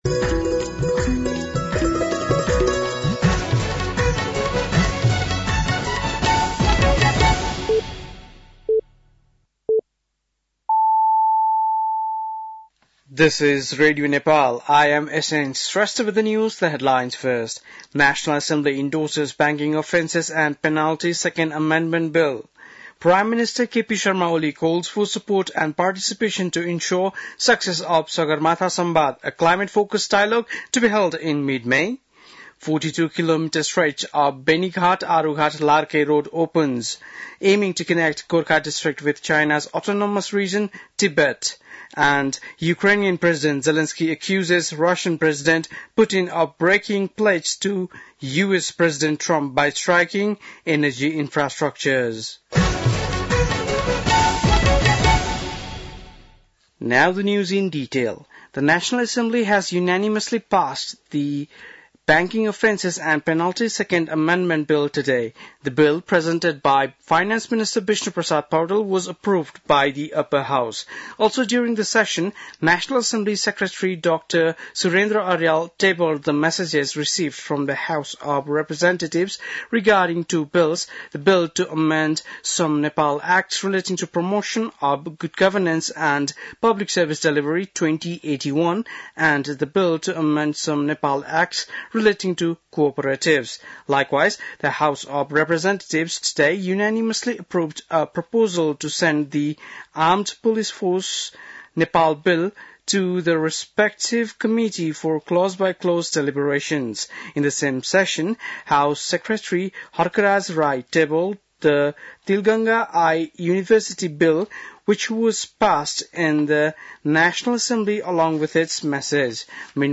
बेलुकी ८ बजेको अङ्ग्रेजी समाचार : ६ चैत , २०८१